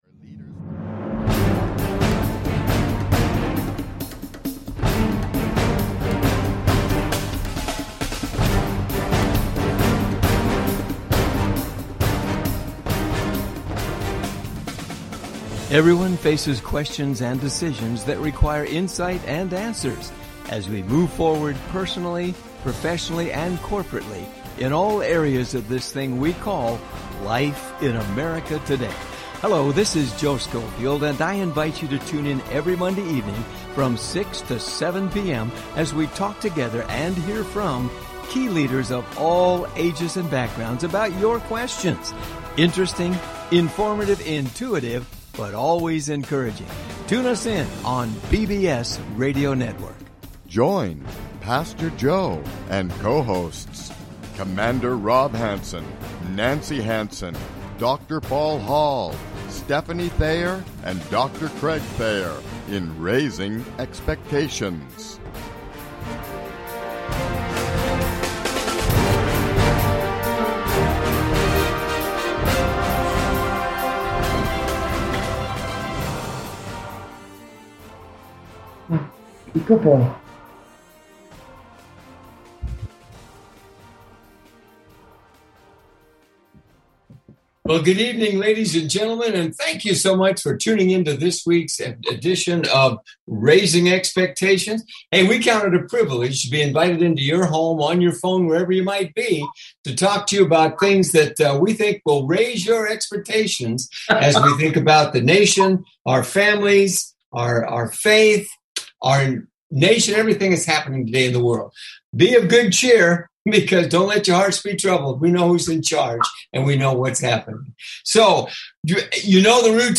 Raising Expectations Talk Show